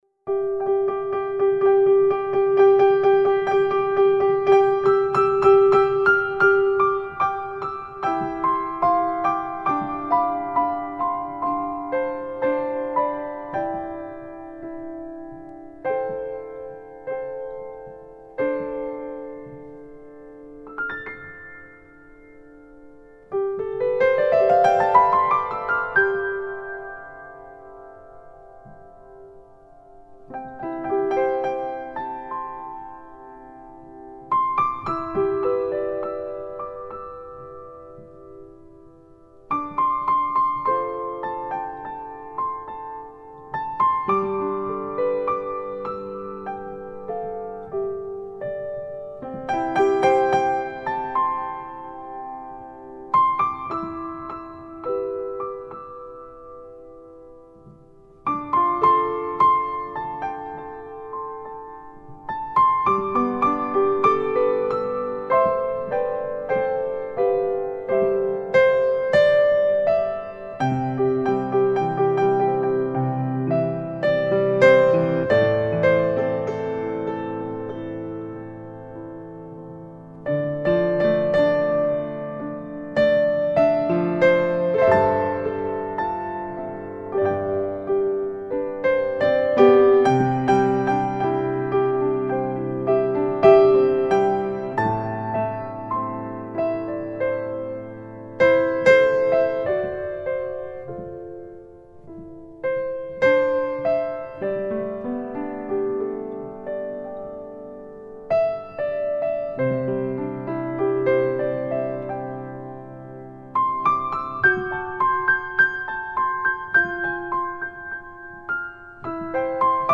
自然传真 真正的示范级三角名琴录音
诗意空灵 如梦似幻的唯美音符
清澈动人的音乐旋律 繁华世界的浪漫琴声